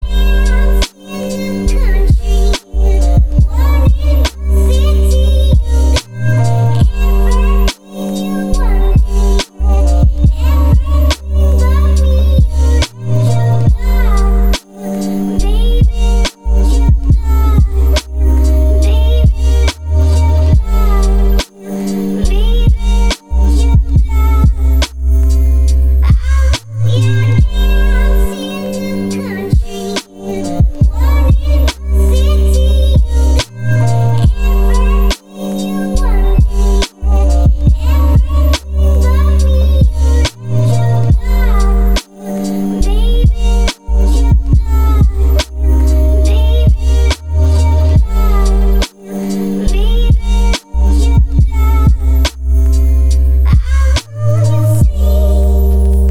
• Качество: 320, Stereo
атмосферные
релакс
Chill Trap
забавный голос
Расслабляющий, релаксный рингтон